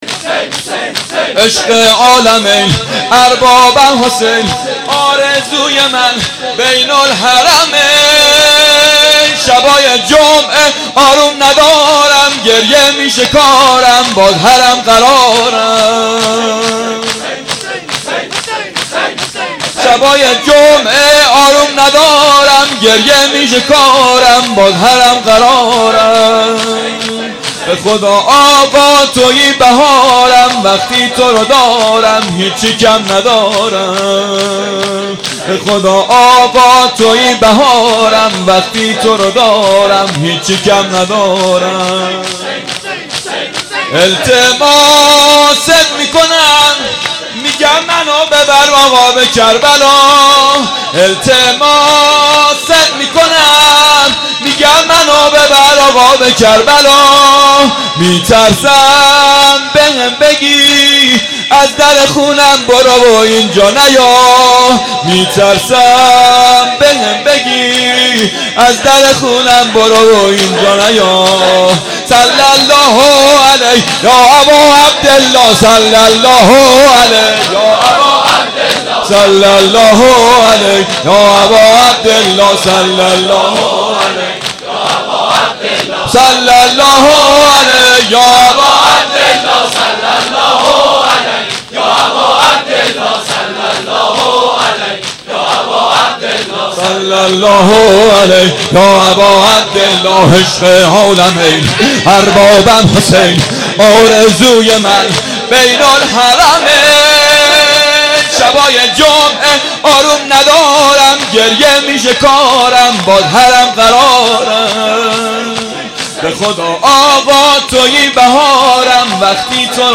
شور شب تاسوعا محرم 94